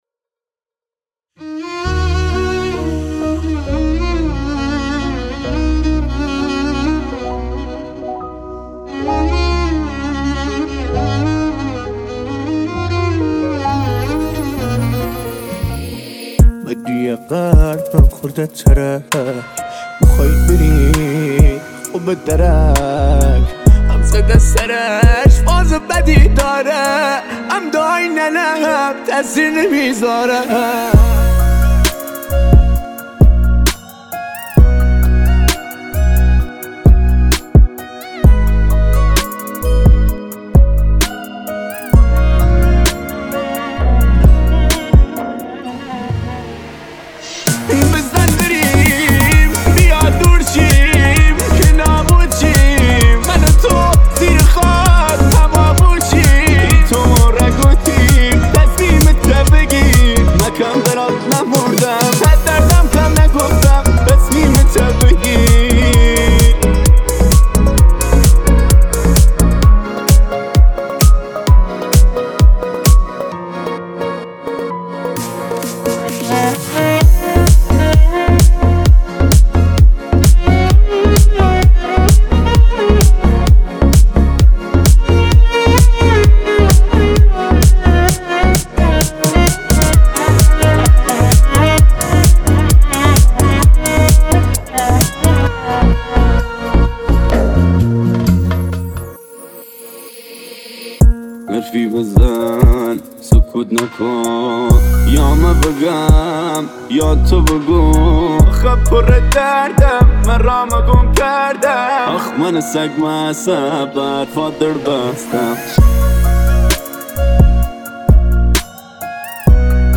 هیپ هاپ